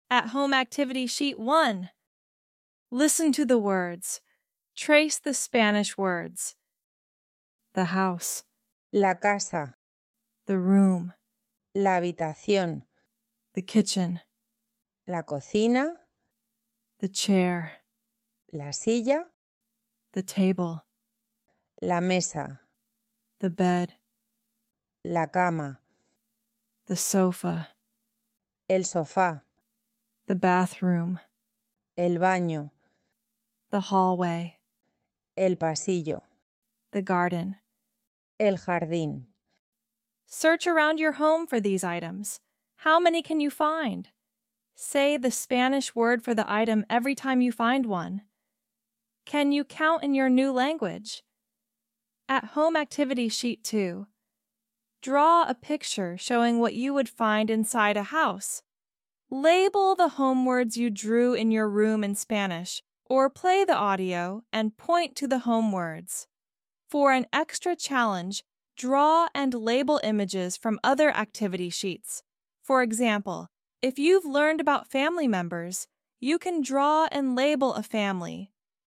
Scroll down to download printable worksheets and an audio pronunciation guide, making home vocabulary learning engaging and effortless.